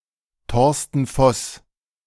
Torsten Voss (sometimes listed as Thorsten Voss; German pronunciation: [ˈtɔʁstn̩ ˈfɔs]